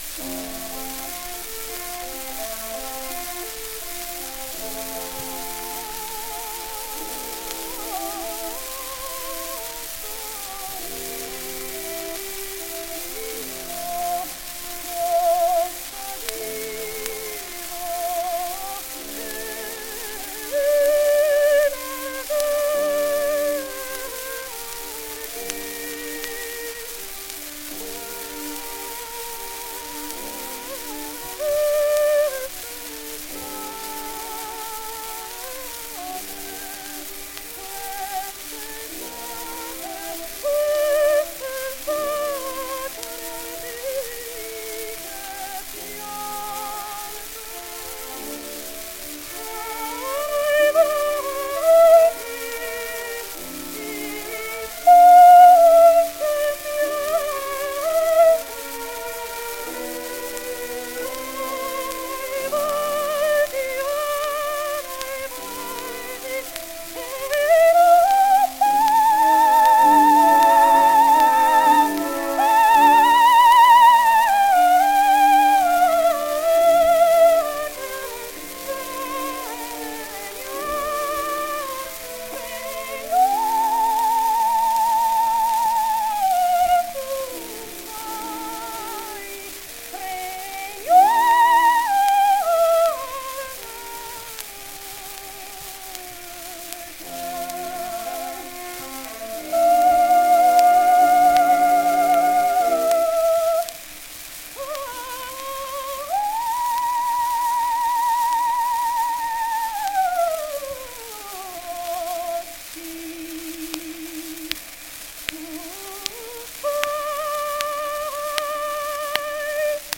Indeed, the soloist Marcella Sembrich, featured in the 1907 Victor recording below of the seminal aria Casta Diva from Bellini’s Norma, came from a poor Austro-Hungarian family and rose to heights like performing at the coveted New York Metropolitan Opera House.
As a result, Sembrich could perform seminal operatic pieces like “Casta Diva” from Bellini’s Norma, displaying her skillful technique and prowess in vocal runs.
12-inch Victrola record